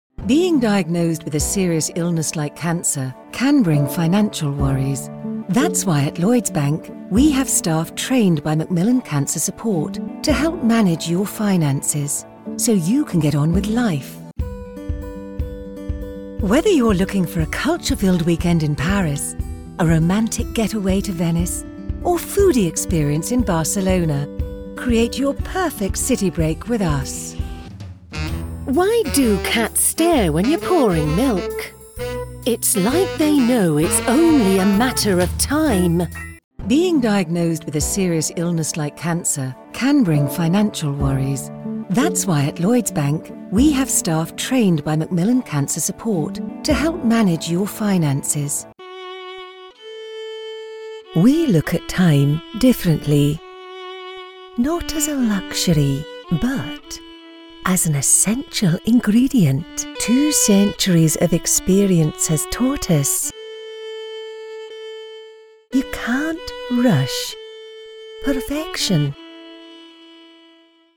Englisch (Britisch)
Warm, Freundlich, Vielseitig, Natürlich, Kommerziell
Kommerziell